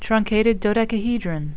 (trun-cat-ed   do-dec-a-he-dron)